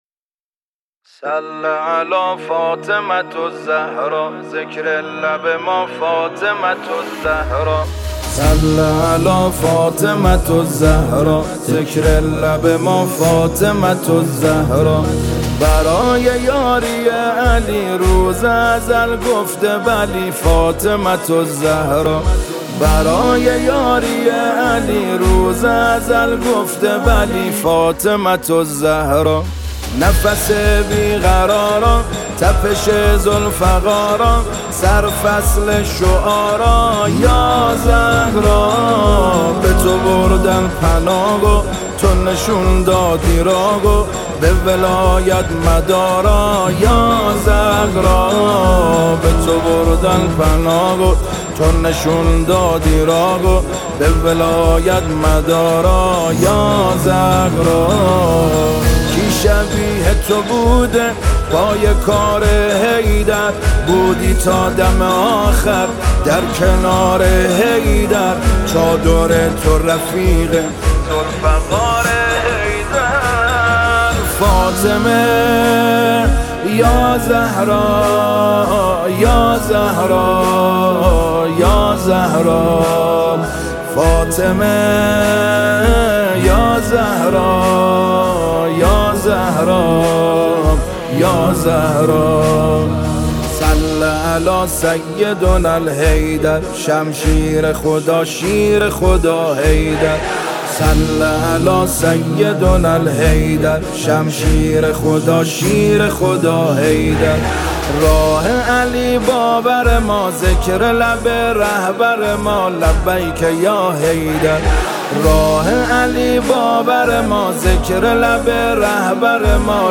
دانلود نماهنگ